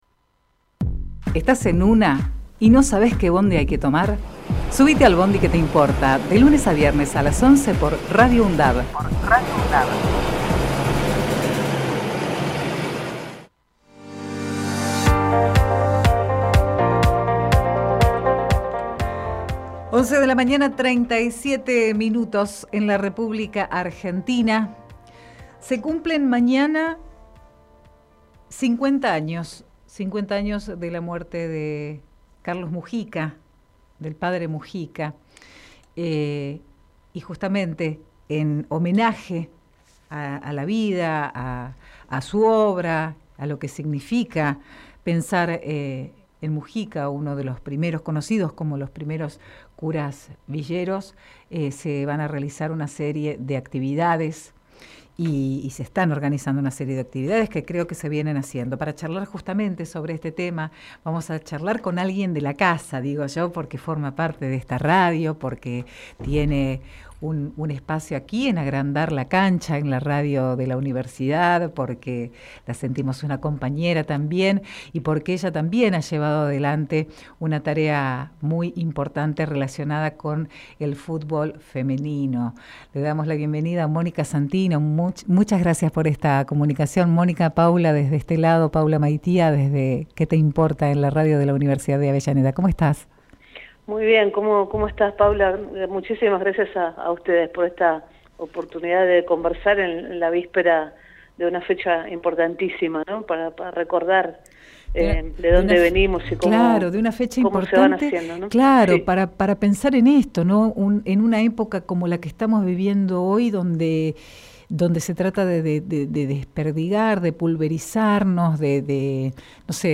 Compartimos entrevista